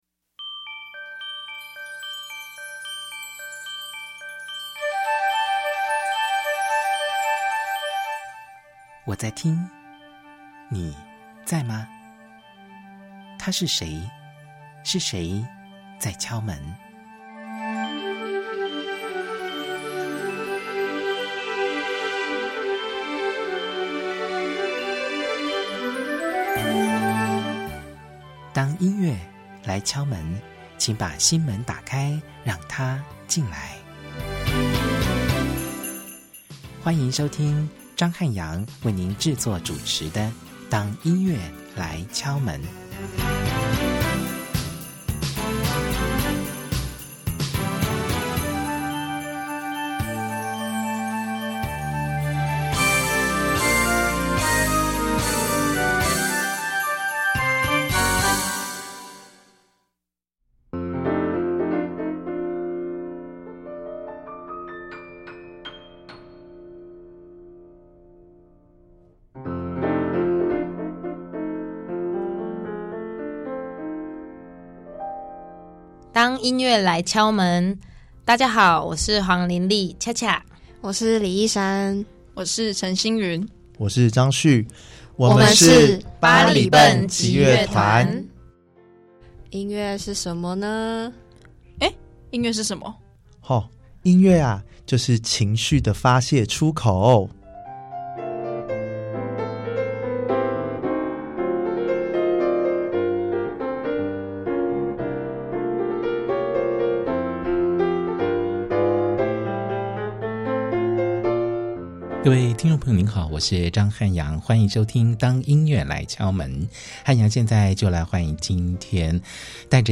訪問